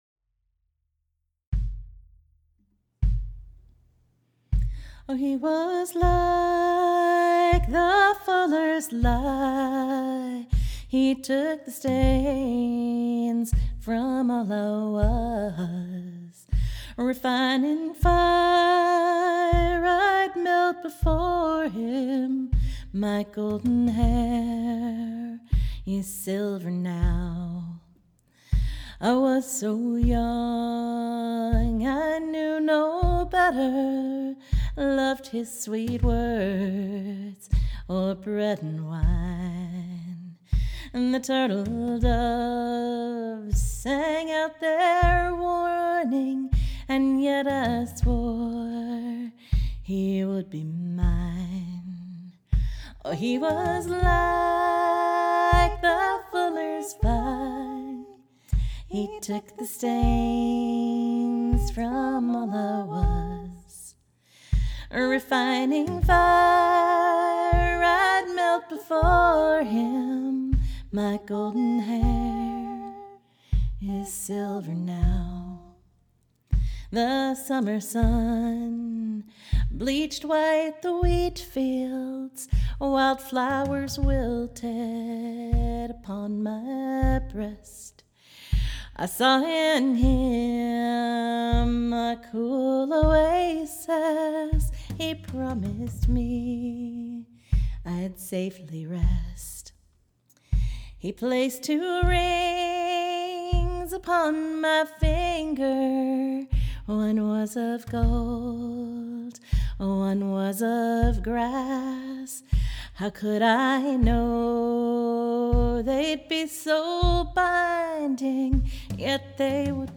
In the style of centuries of lyrical cautionary tales, this one is about being young and mistaking false love for divinity. Inspired by a snippet of scripture, English broadsides, and centuries of tragic love songs.